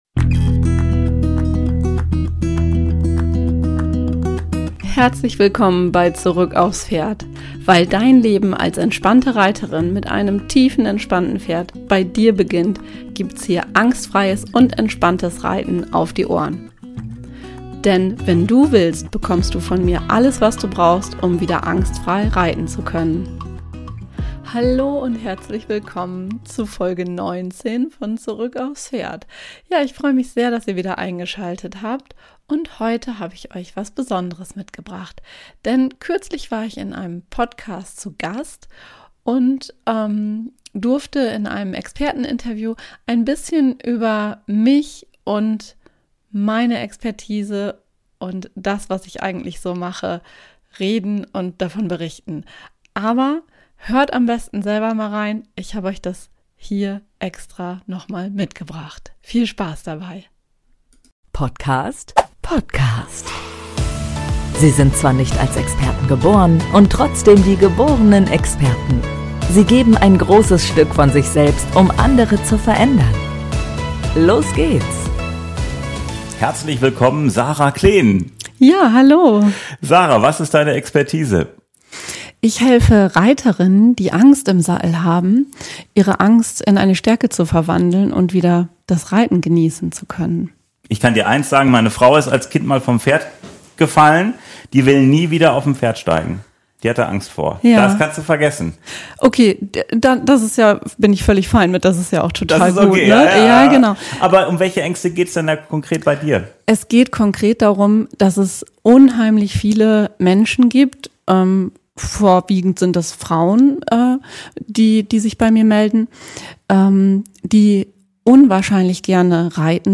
In dieser Podcastfolge habe ich dir den Mitschnitt von einem anderen Podcast, bei dem ich im Experteninterview war, mitgebracht. Es geht natürlich um mein Herzensthema!